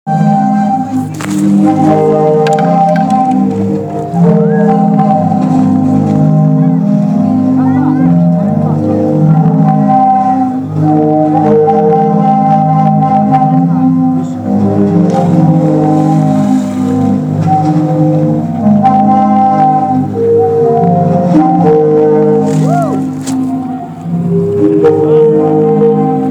One of the reasons we came to Zadar was to look at a couple of art installations on the sea front –  the Sea Organ which produces sounds as waves push air through a series of pipes on the promenade, and the Sun Salutation which stores energy from the sun during the day to produce a light show at night.